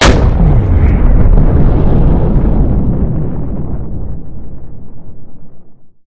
rocket.ogg